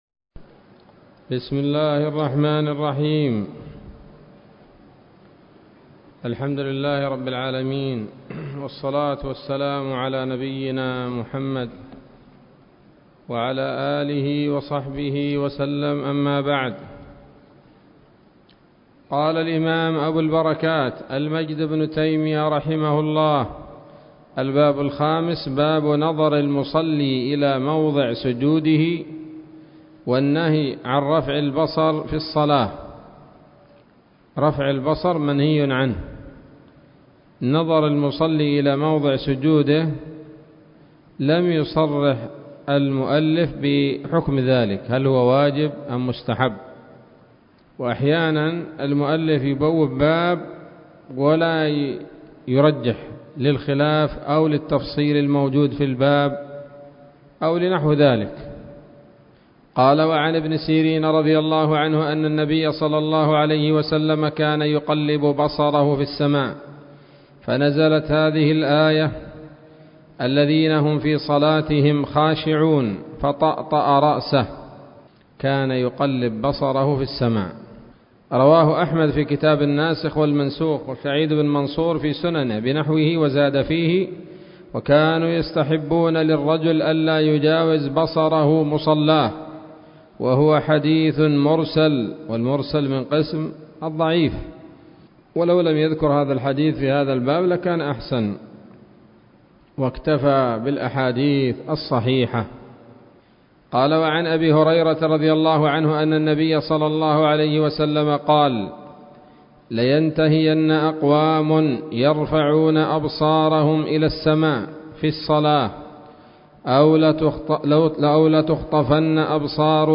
الدرس الرابع عشر من أبواب صفة الصلاة من نيل الأوطار